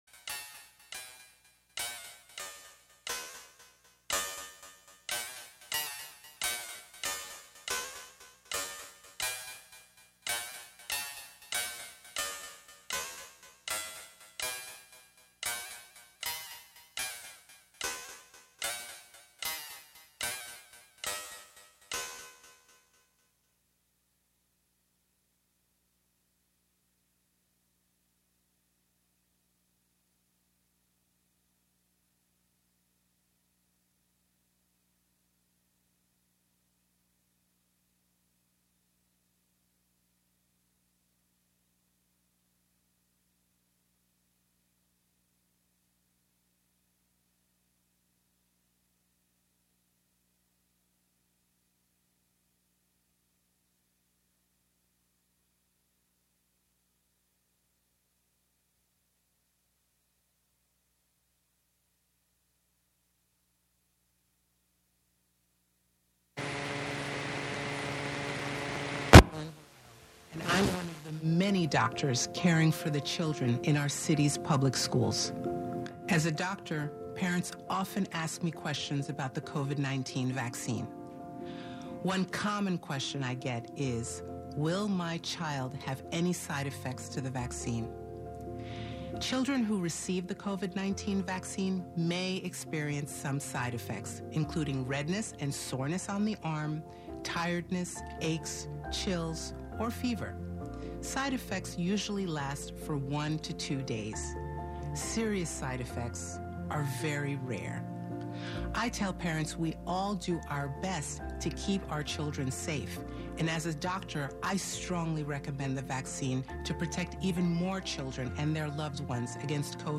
11am Live from Brooklyn, New York
making instant techno 90 percent of the time